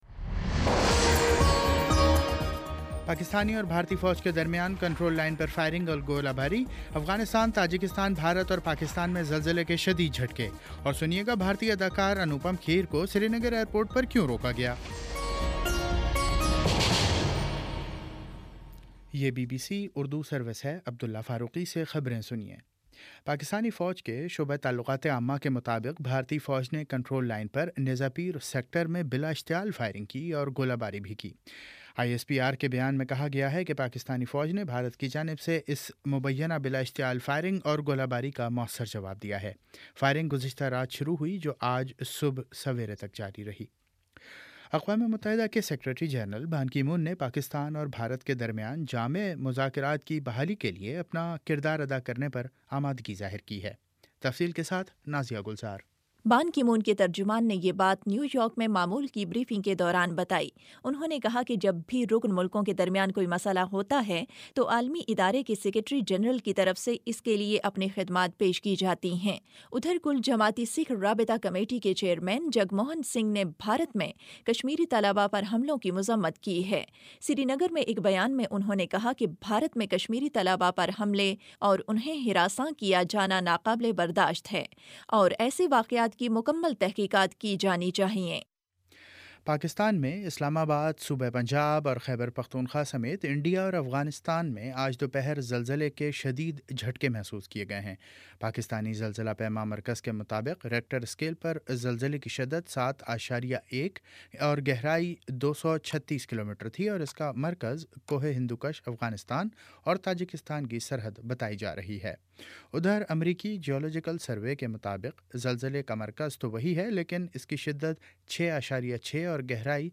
اپریل 10 : شام پانچ بجے کا نیوز بُلیٹن